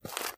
STEPS Dirt, Walk 22.wav